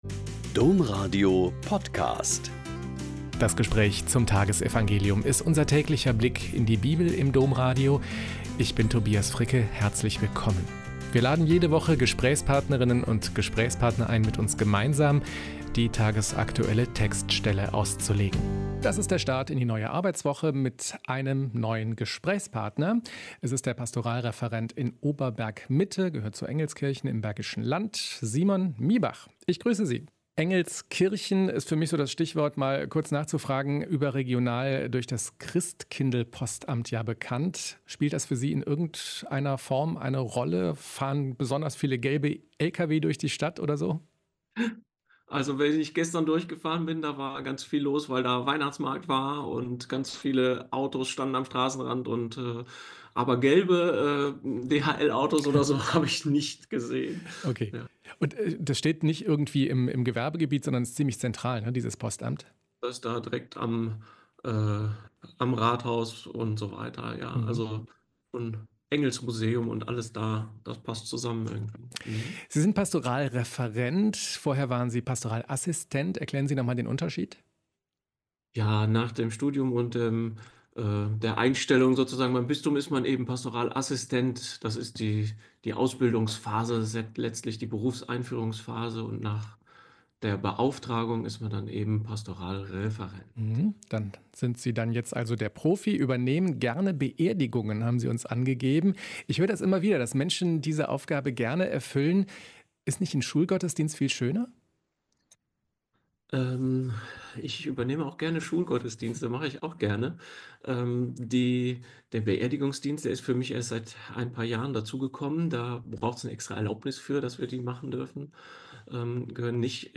Mt 21,23-27 - Gespräch